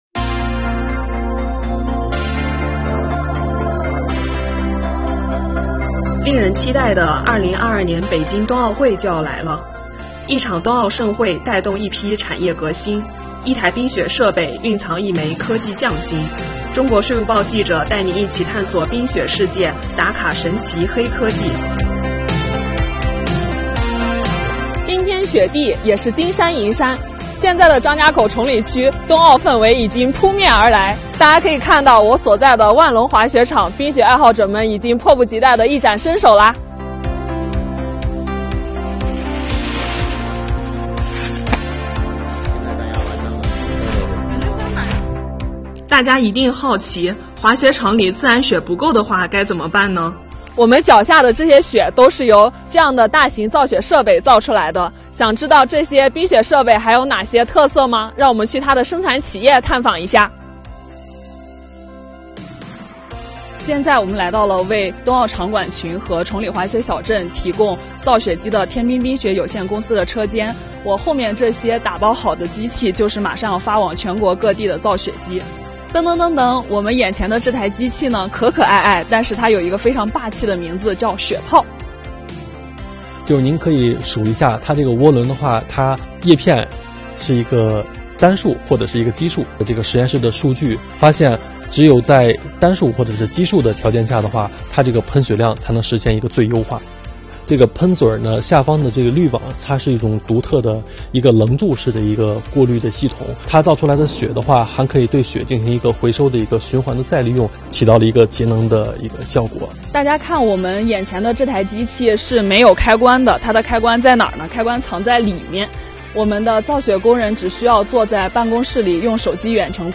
让我们跟随记者，一起来走进崇礼，探秘冬奥背后的黑科技～～